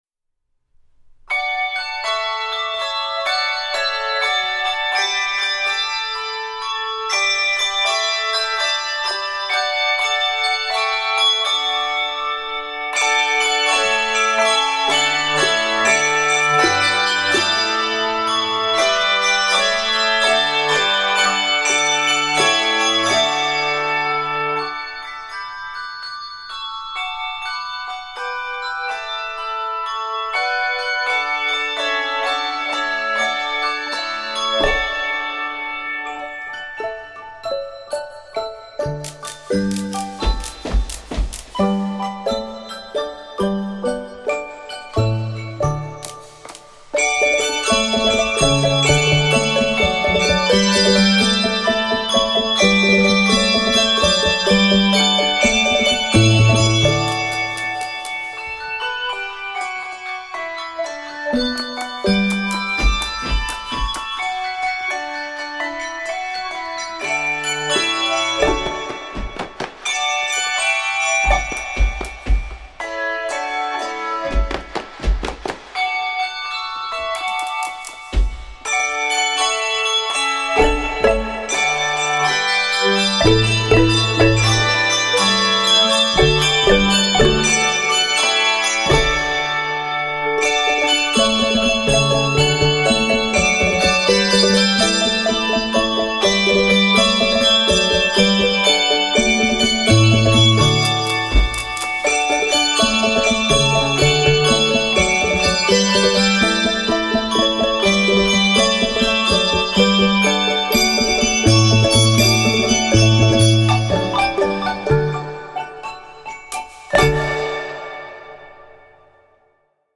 exciting, rhythmic musical exultation
Key of D Major.